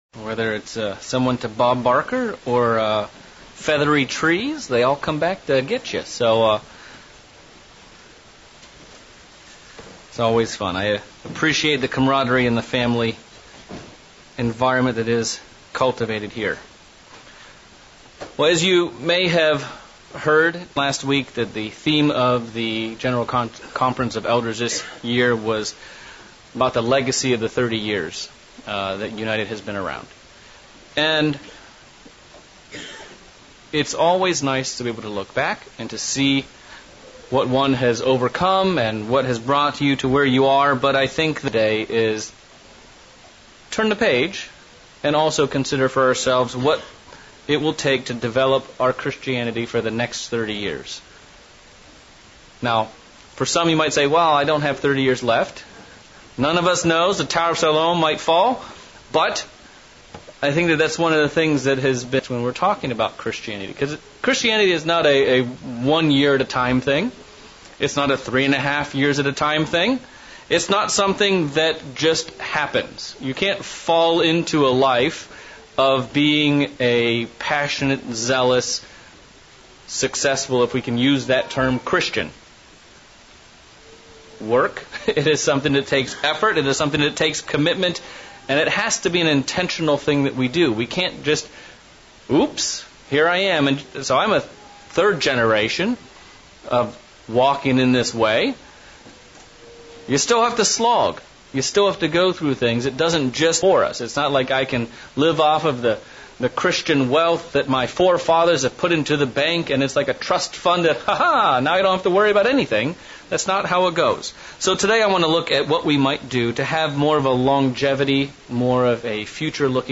Sermons
Given in Central Illinois